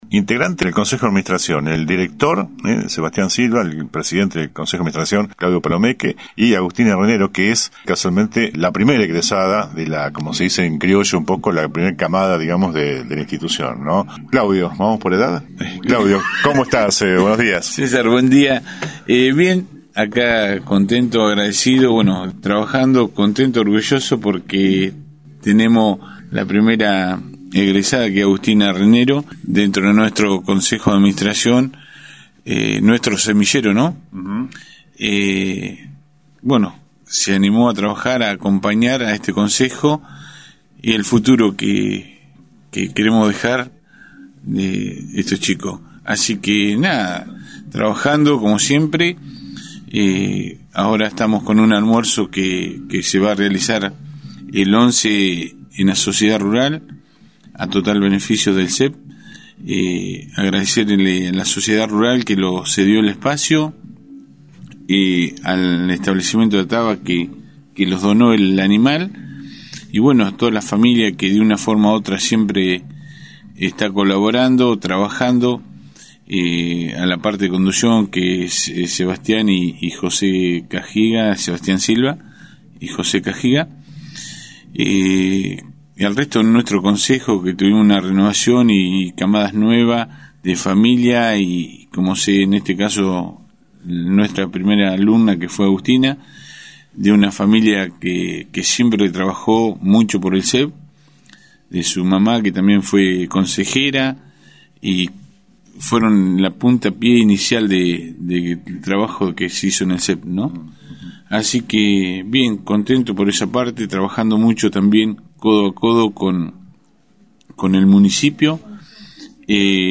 Por esto, llegaron al piso de la radio